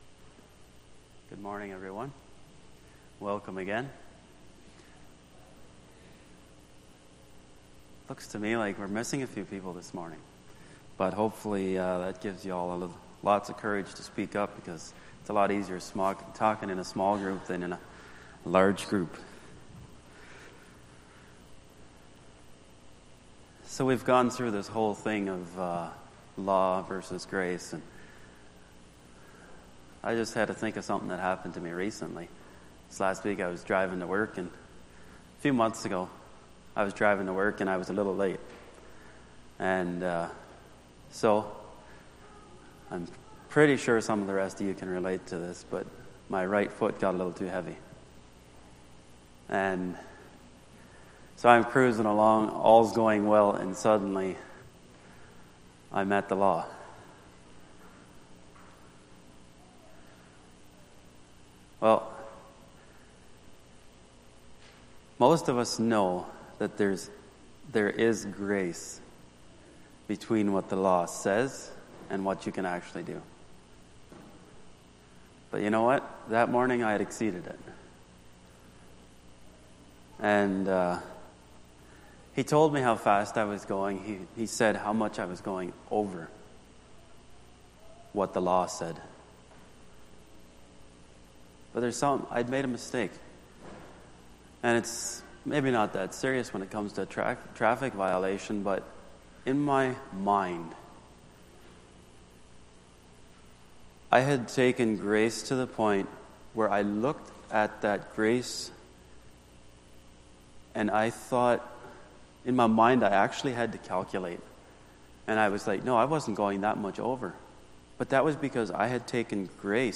Sunday Morning Bible Study